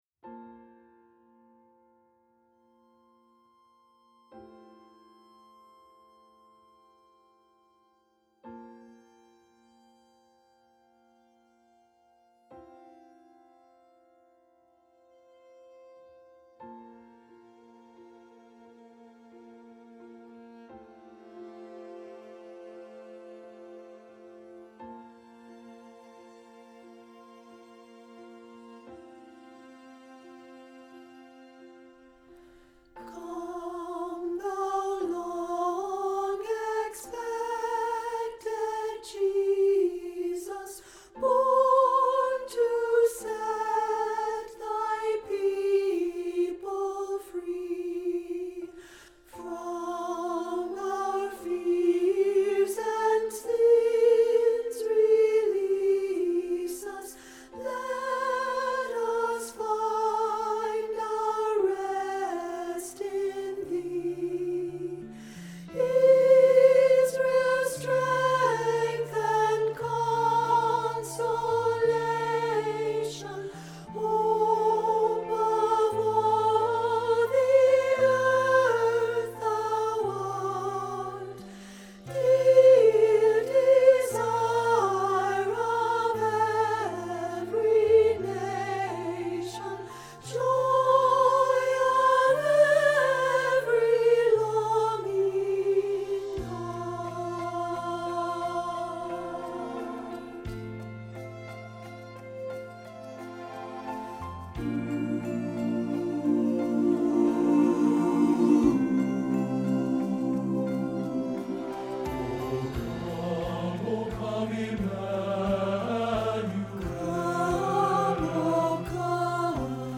O Come - Alto 2015-10-25 Choir